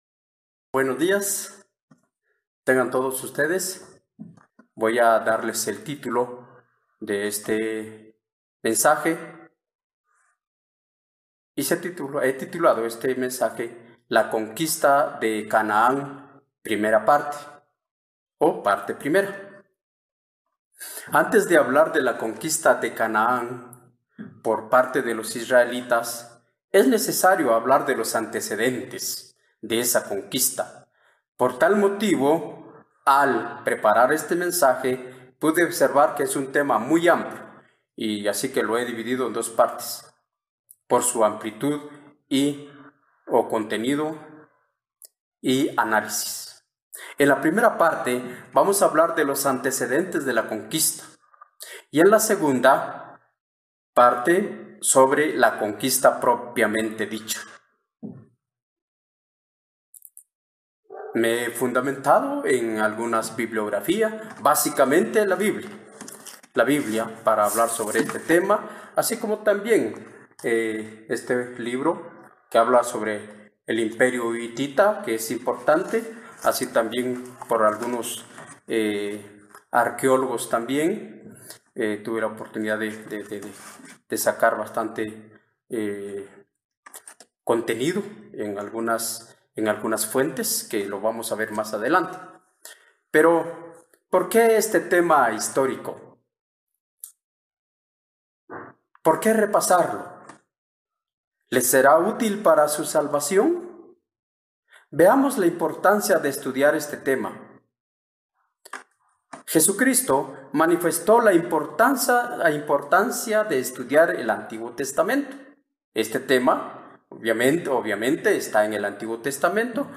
La conquista de Canaán fue una hazaña en la que intervinieron múltiples factores, todos ellos relacionados con la mano de Dios en los eventos mundiales. Mensaje entregado el 6 de marzo de 2021.
Sermones